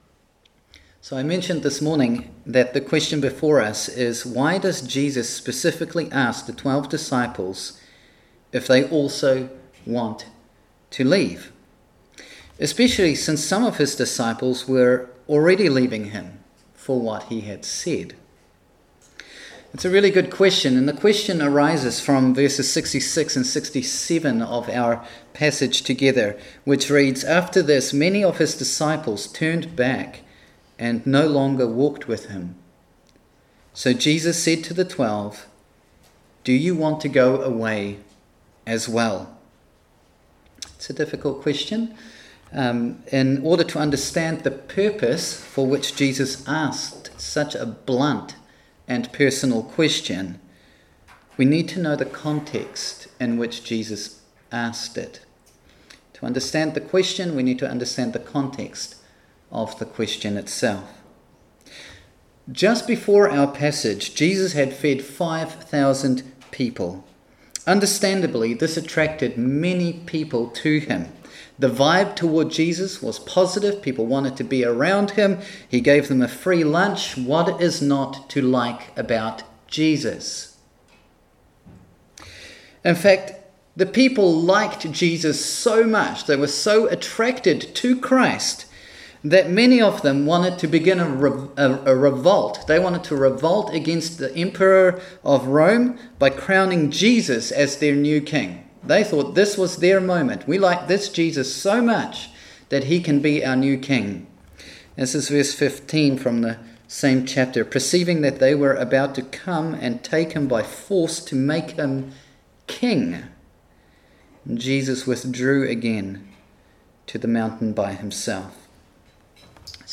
May 23, 2021 Tested Loyalties | John 6:22-71 MP3 SUBSCRIBE on iTunes(Podcast) Notes Sermons in this Series Tested Loyalties | John 6:22-71 Speaking In Tongues | Selected Scriptures Sex Before Marriage